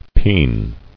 [peen]